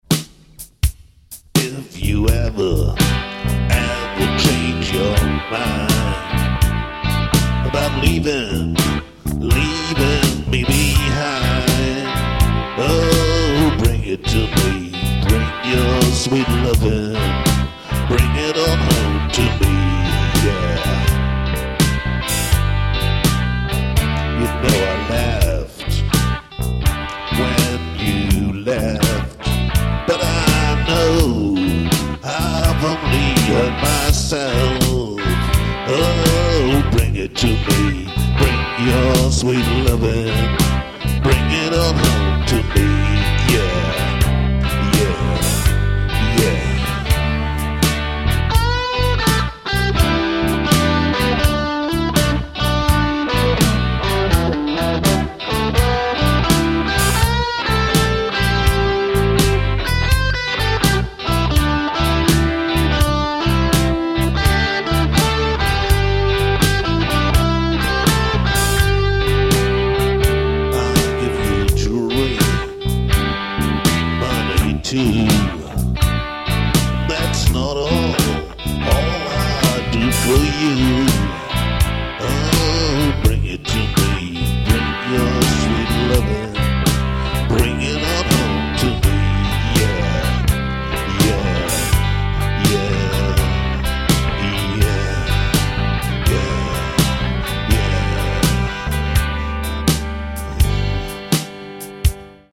Aufgenommen mit Roland BR800 (in Experimentierphase!!!). Alle Gitarren sind meine Rockinger Telecaster mit verschiedenen Amp-Modellings. Die Kompression der Sologitarre kommt nicht durch einen Effekt, sondern einzig durch den eingebauten DiMarzio X2N!!! Der Bass ist ein Maruszczyk Ellwood mir Delano JB-Humbuckern und BR800 Amp modeling. Schlagzeug ist 100% BR800...
Ende des Songs: Extrem ausbaufähig!!!